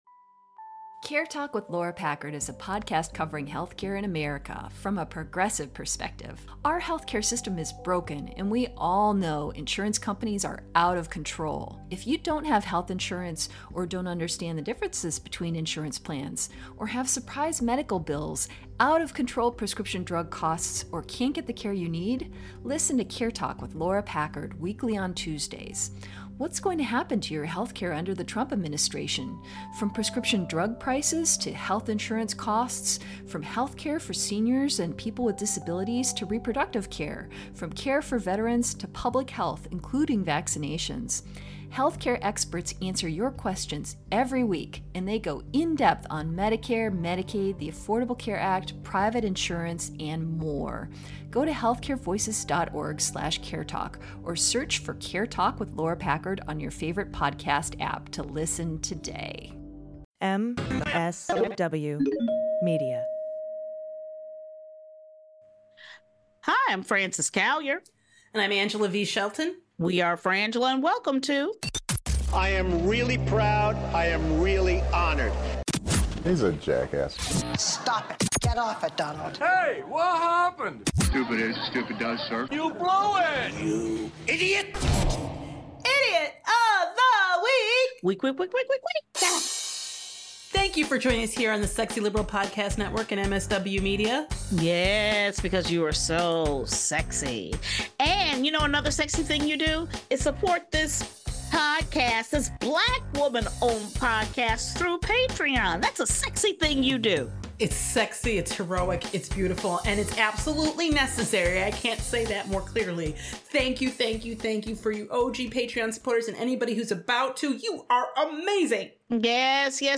Society & Culture, News, Comedy